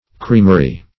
Creamery \Cream"er*y\ (-?r-?), n.; pl. Creameries (-?z). [CF.